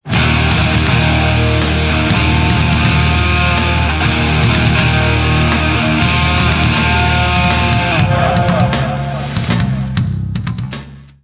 Distortion 1 (88 kb, 10 sec)